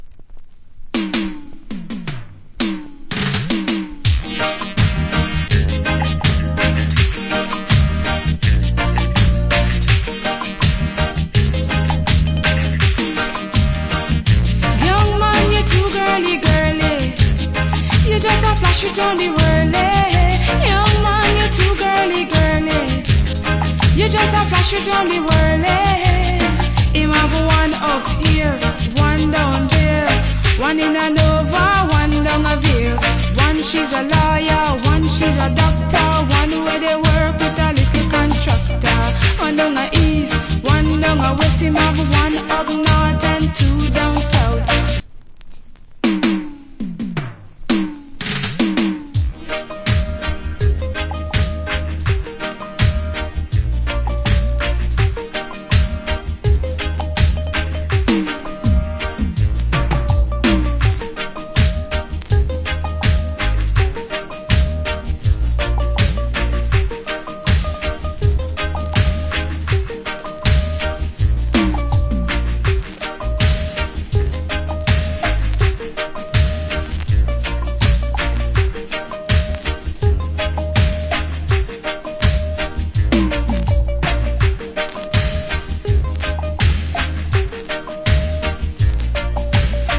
80's. Nice Female Dancehall Vocal.